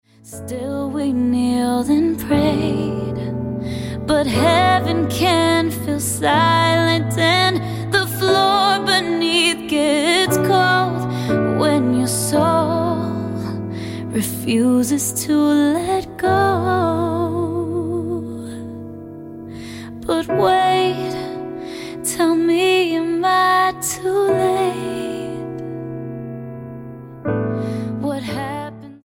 STYLE: Gospel
breathtaking tearjerker